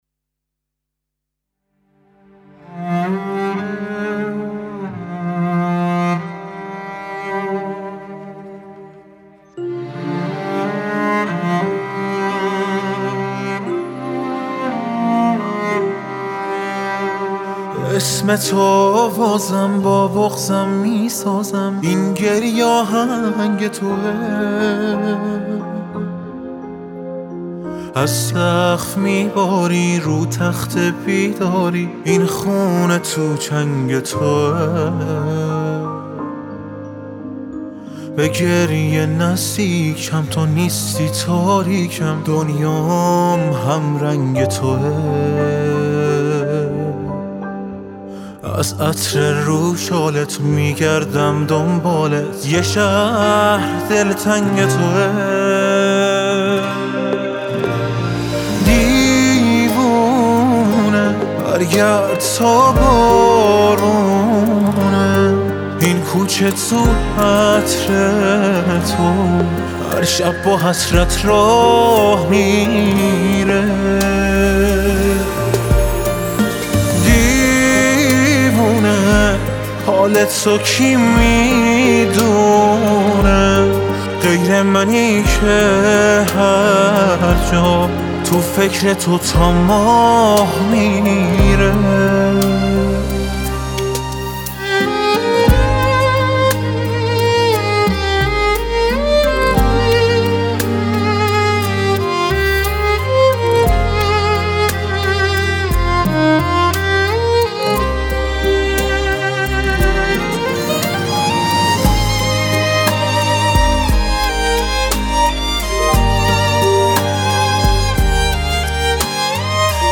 آهنگ آرام